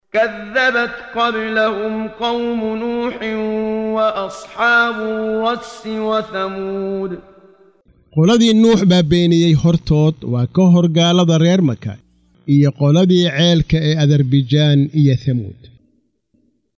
Waa Akhrin Codeed Af Soomaali ah ee Macaanida Suuradda Qaaf oo u kala Qaybsan Aayado ahaan ayna la Socoto Akhrinta Qaariga Sheekh Muxammad Siddiiq Al-Manshaawi.